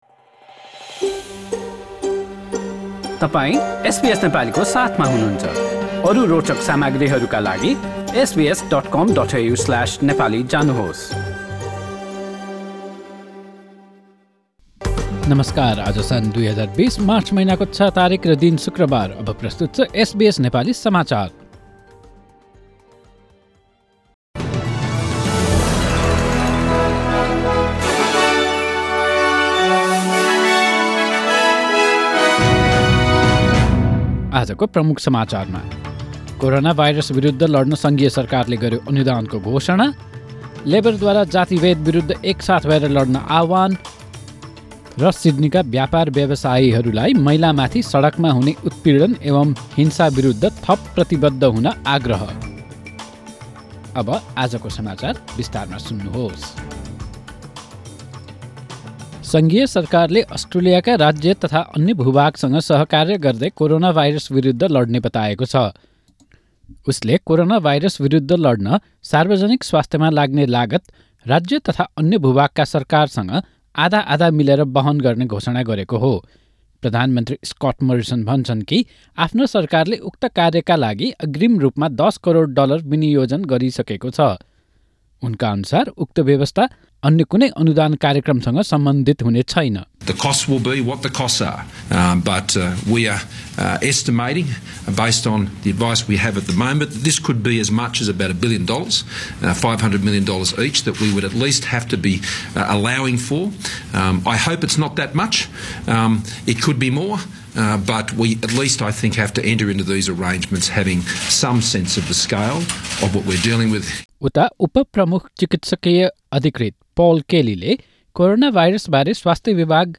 Listen to the latest news headlines in Australia from SBS Nepali radio - In this bulletin, ** The federal government announces funding to help tackle the coronavirus...